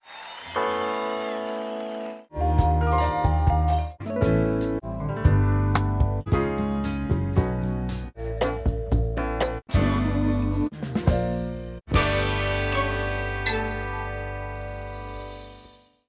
Mu major example: sound collage
(stereo, 16 bits, 11kHz)